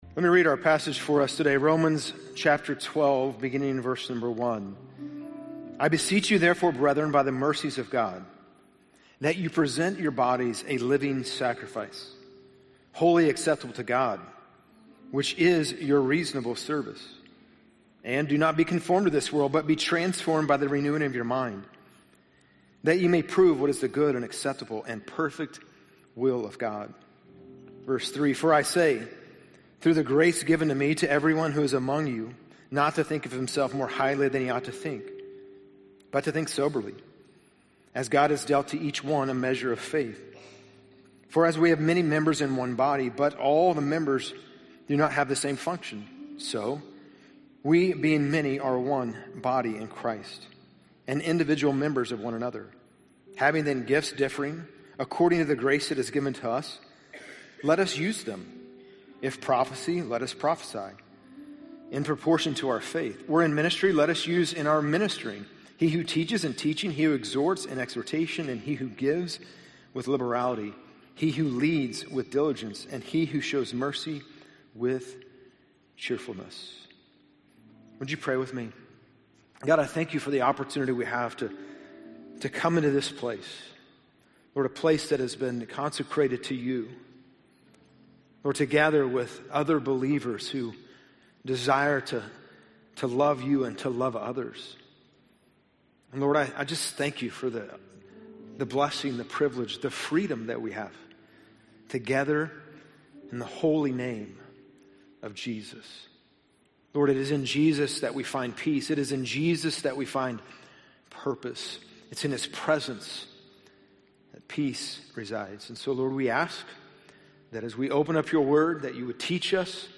Missing Peace #5 - Peace Through Purpose - Sermons - Hallmark Church